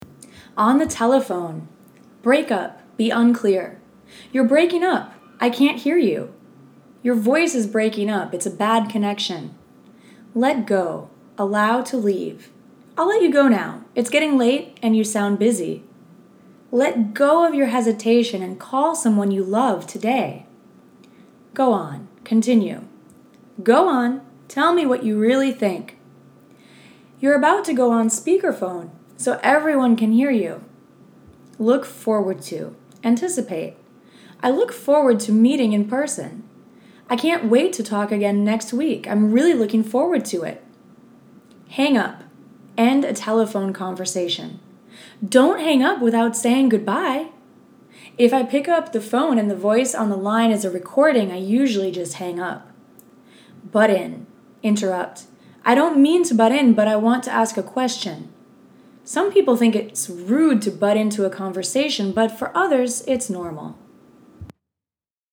Free sample lesson